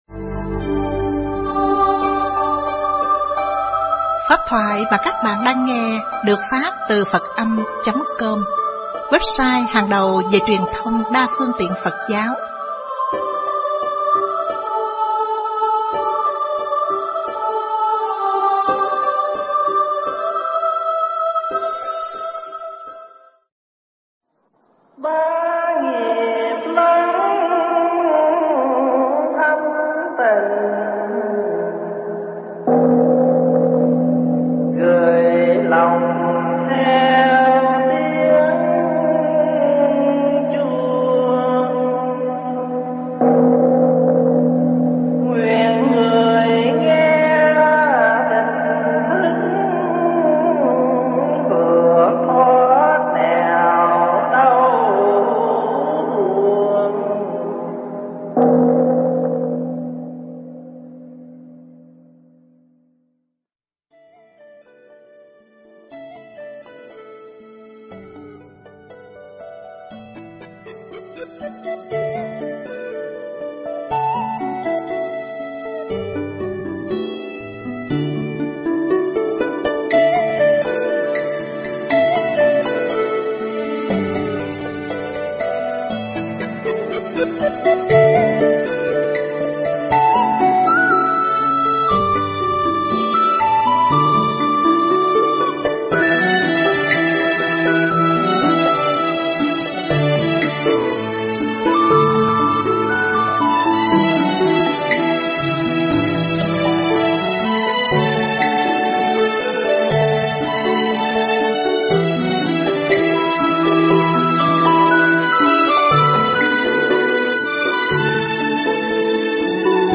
Nghe Mp3 thuyết pháp Sống Sao Cho Yên Tịnh Để Hết Sầu 1